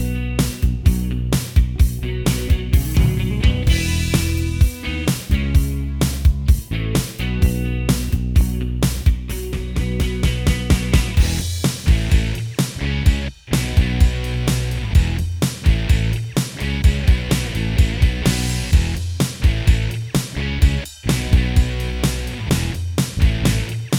Minus Main Guitar Pop (1980s) 2:52 Buy £1.50